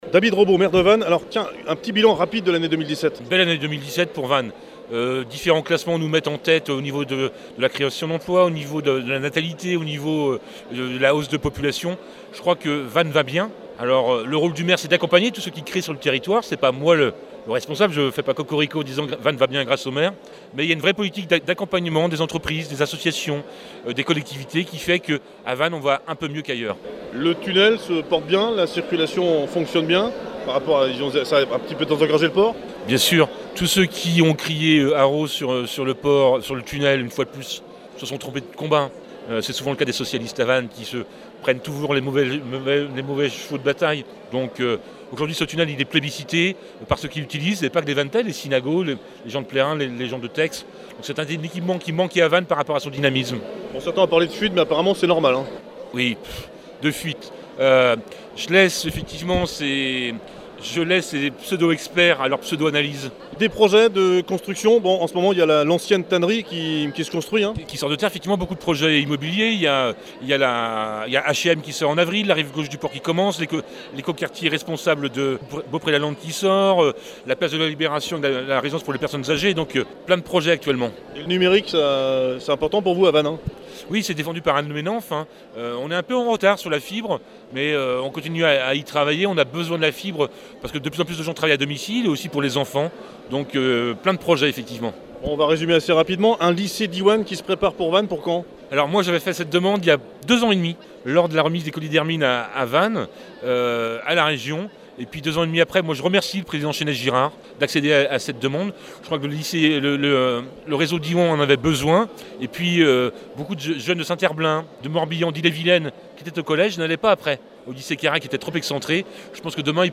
VANNES projets 2018 et voeux – Interview de David Robo – Maire